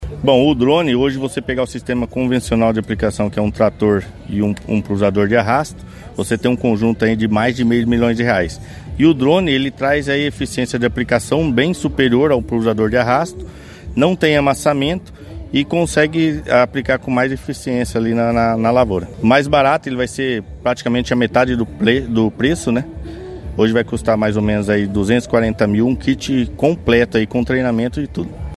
O especialista em agricultura de precisão